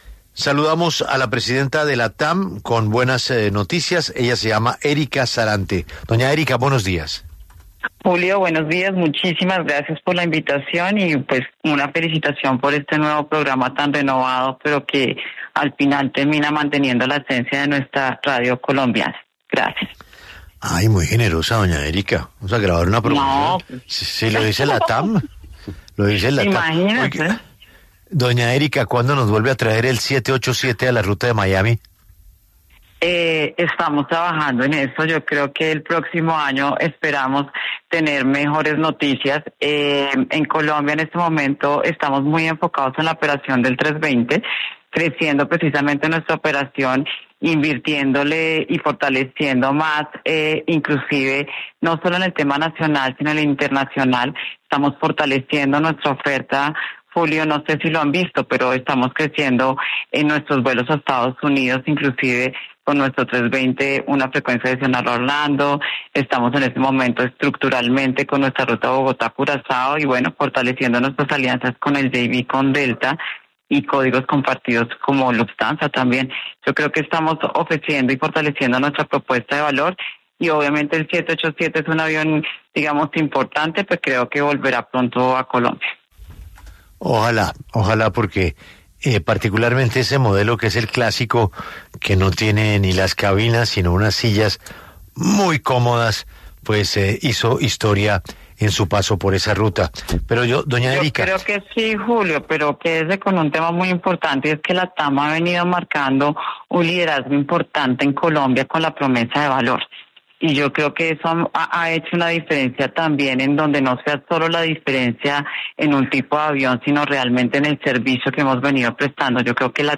En diálogo con Julio Sánchez Cristo